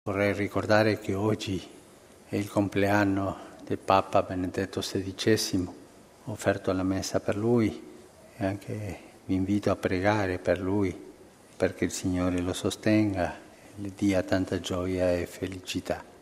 E stamani, Papa Francesco ha offerto la Messa mattutina a Casa Santa Marta proprio al suo predecessore, con parole di speciale augurio: